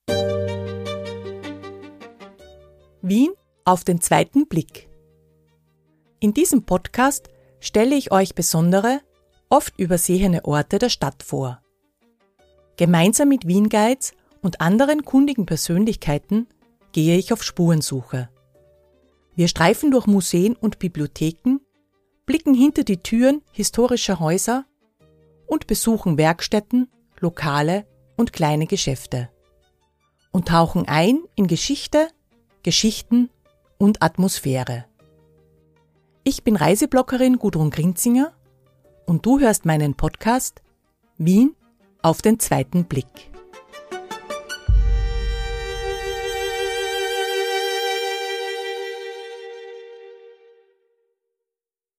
Jede Folge entsteht direkt vor Ort: Gemeinsam mit